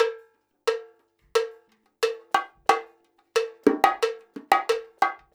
90 BONGO 3.wav